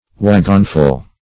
Search Result for " wagonful" : The Collaborative International Dictionary of English v.0.48: Wagonful \Wag"on*ful\, n.; pl. Wagonfuls . As much as a wagon will hold; enough to fill a wagon; a wagonload.
wagonful.mp3